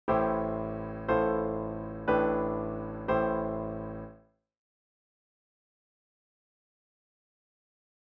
For example, this progression is that same C F G C progression, but with an A pedal tone as the lowest-sounding note:
C/A  F/A  G/A  C/A